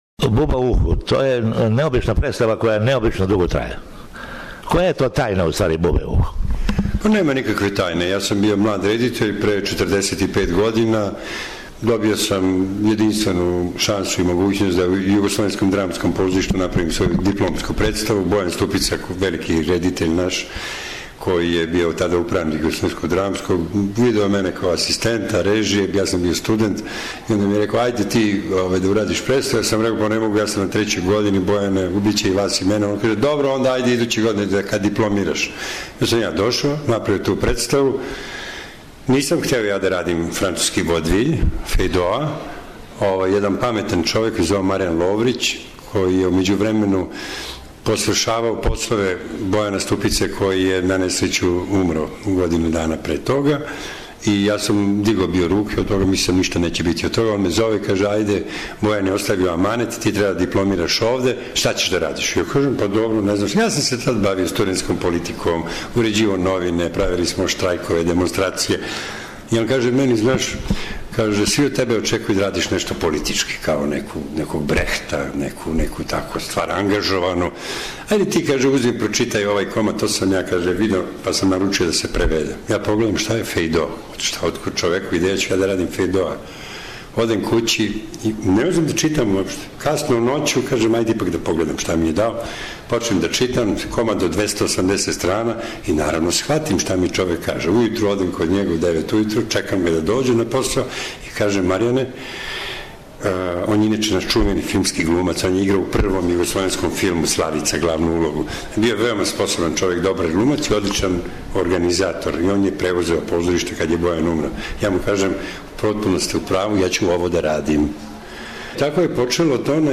"Buba u uhu" is the longest-running play in the history of Serbian theater. Director Ljubiša Ristić talks exclusively to SBS.